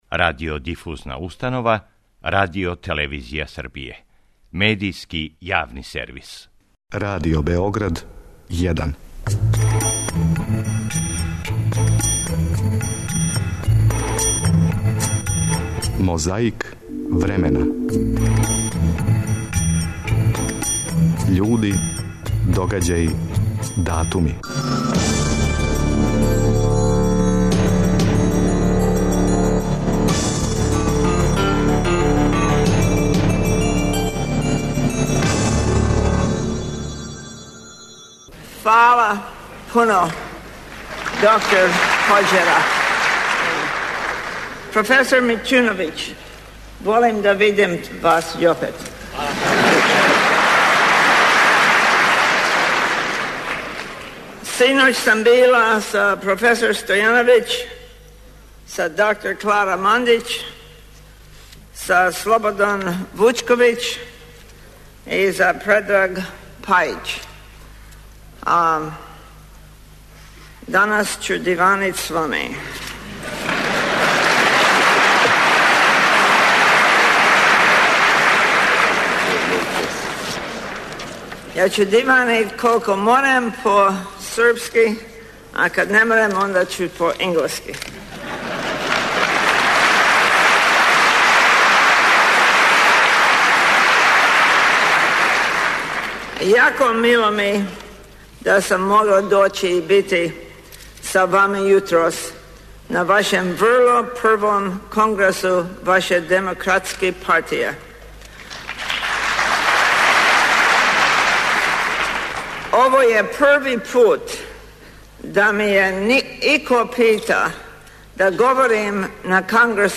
Председавајући, Љубомир Тадић ју је најавио, а на почетку емсије, слушамо шта је микрофон забележио.
У Љубљани је 27. септембра 1971, на Четвртом конгресу југословенских бораца из редова међународних бригада у Шпанском грађанском рату, говорила је Долорес Ибарури.
И домаћин и гост одржали су здравицу.